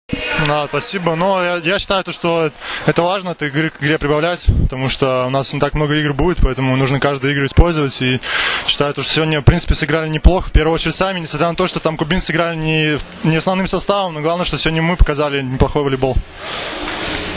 IZJAVA MAKSIMA MIHAJLOVA